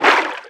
Sfx_creature_featherfish_swim_fast_01.ogg